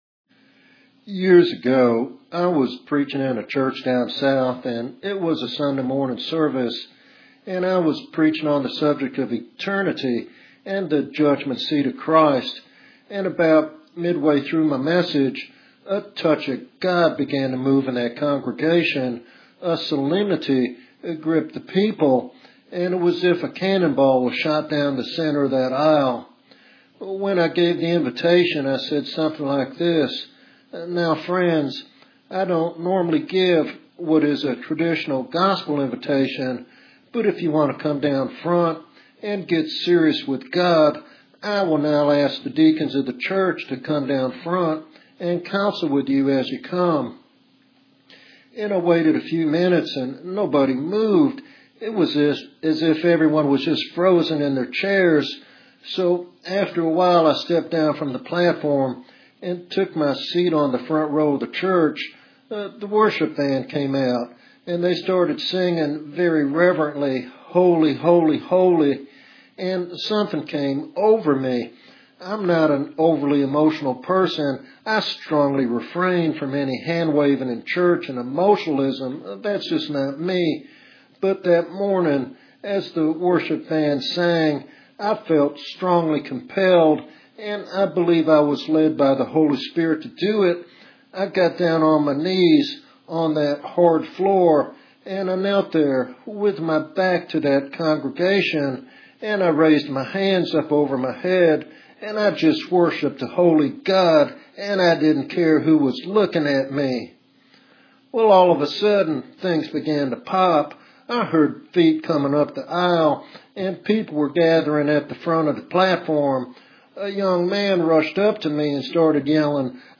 This sermon emphasizes the necessity of preaching Christ crucified and the urgent need for Holy Spirit revival to awaken the church and the nation.